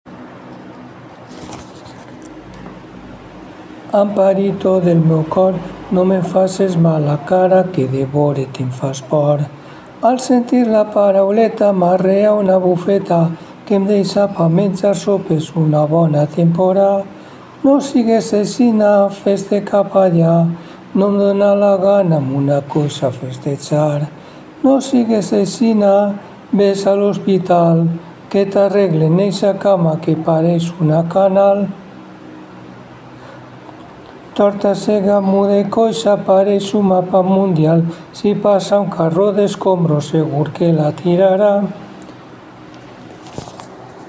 “Amparito del meu cor”, cançó del darrer quart del segle XIX